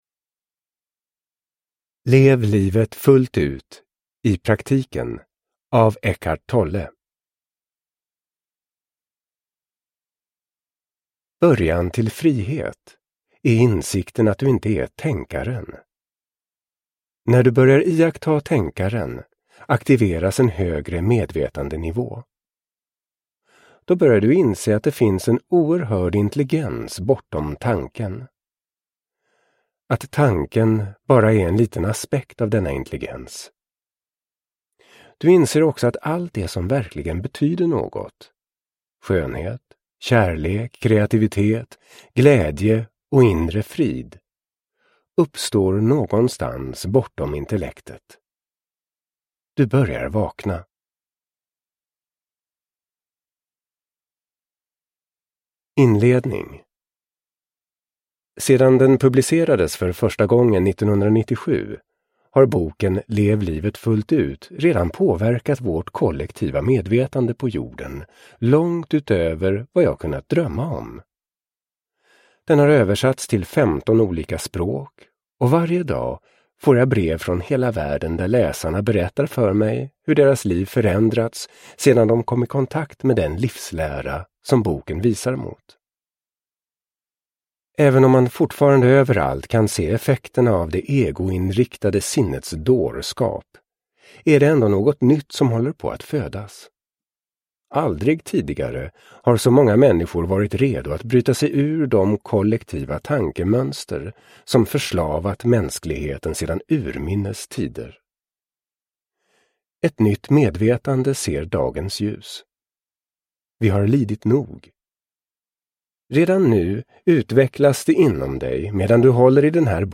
Lev livet fullt ut i praktiken : meditationer, övningar och principer för ett frigjort liv – Ljudbok – Laddas ner